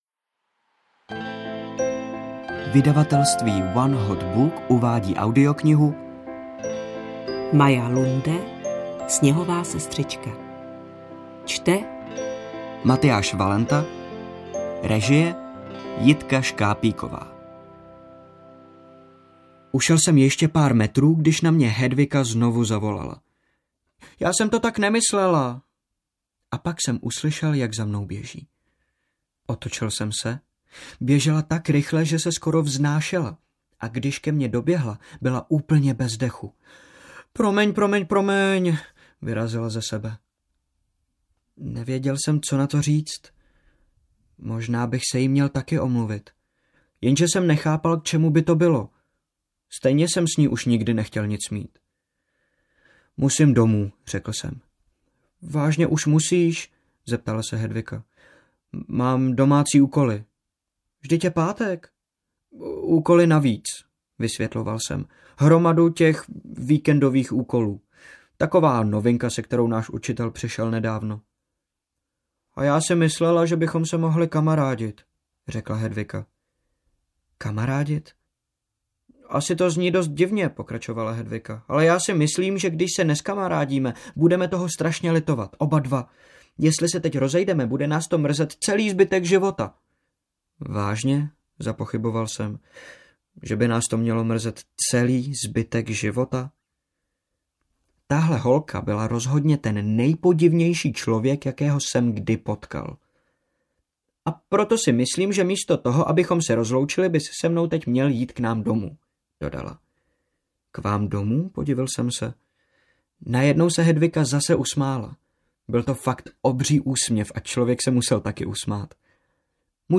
Sněhová sestřička audiokniha
Ukázka z knihy